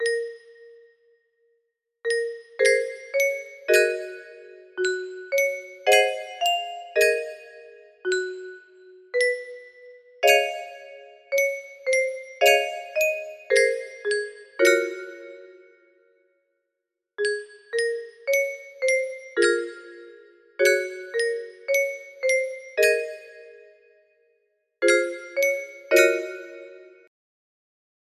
Saint Barbara music box melody